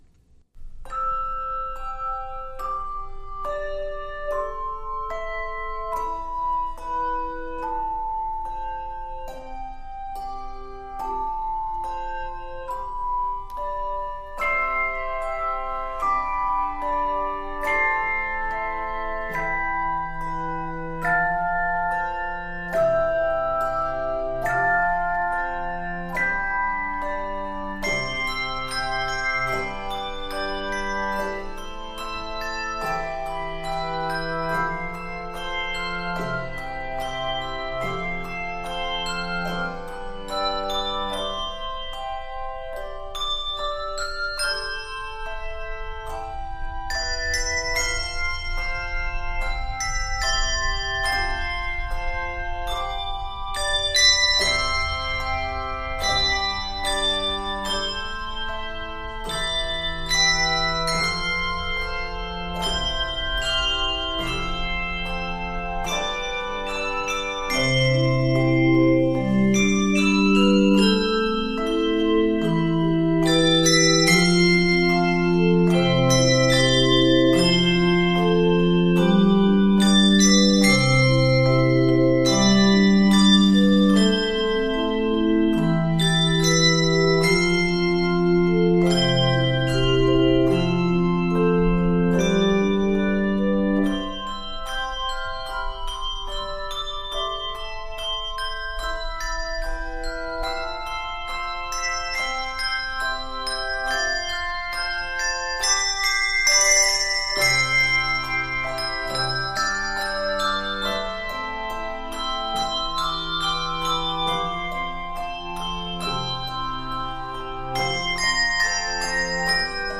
Key of C Major.
Octaves: 3-5